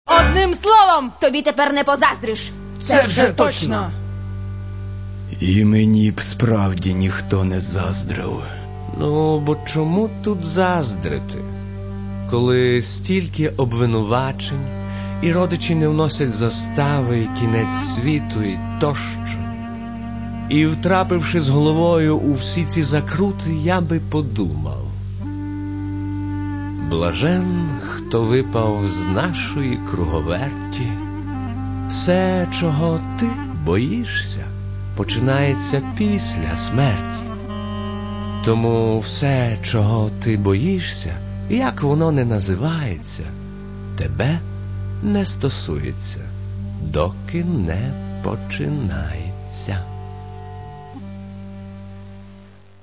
Не відчуваєш в них ніяких прикрас, ніякої гри, все – правда.
скрипка
акордеон
контрабас
кларнет